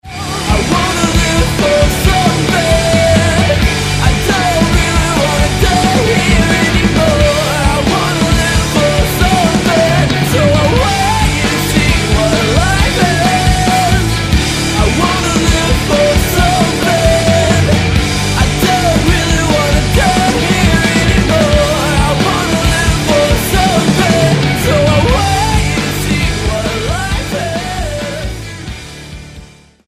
STYLE: Rock
dense, guitar-layered rock
Falling somewhere between power pop and metal